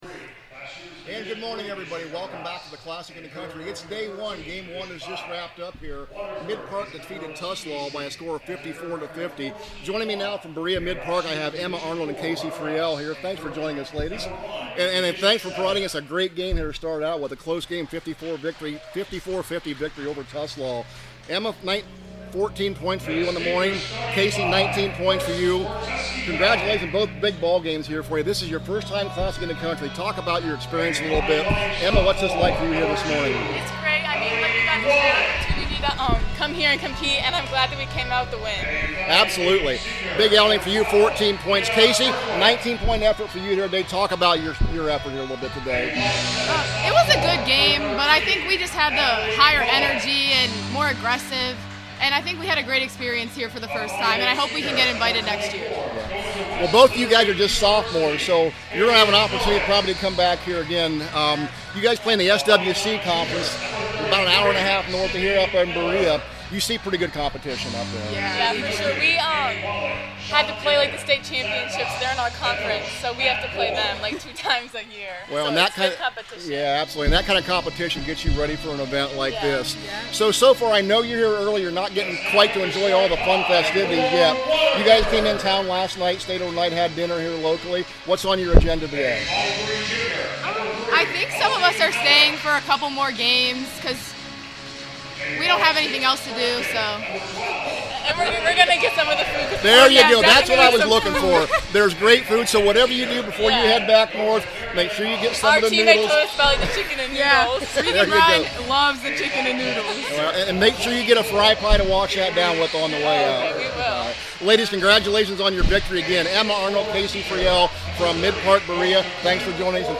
2025 CIC – Midpark Player Interviews